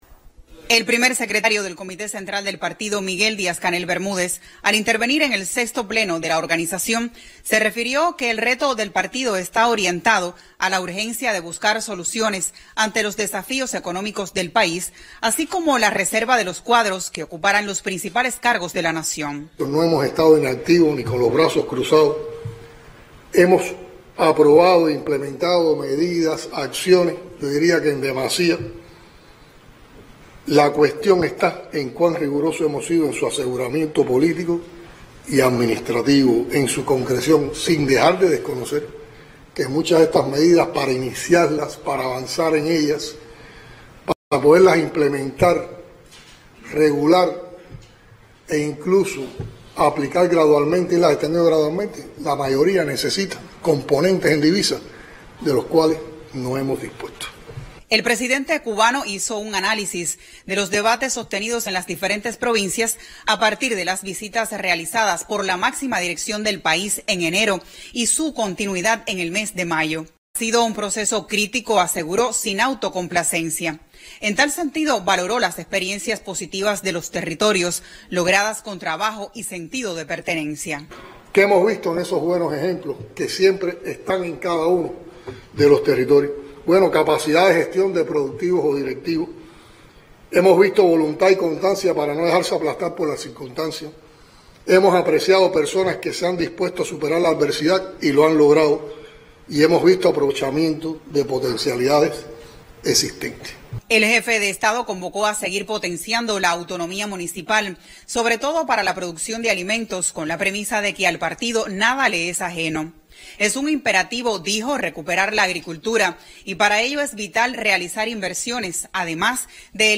Discursos
canel-vi-pleno-del-cc-del-pcc.mp3